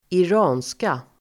Uttal: [p'är:siska]